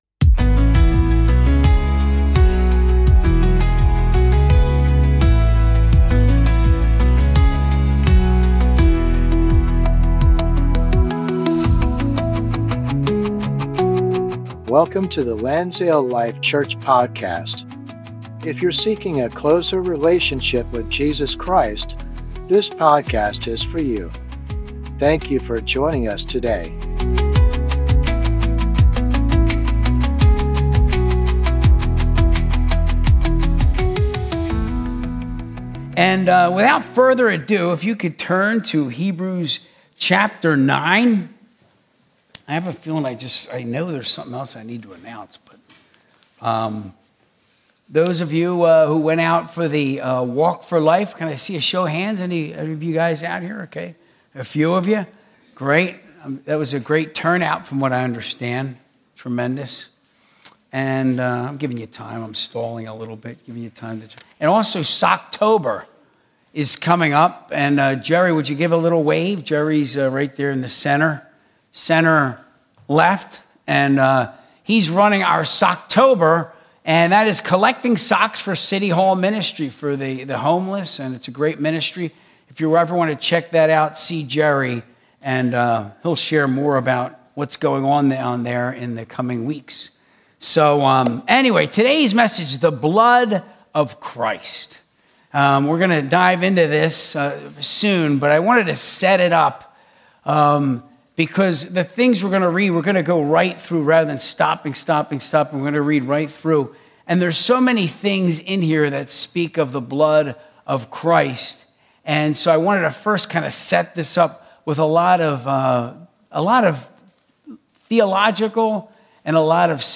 Sunday Service - 2025-10-12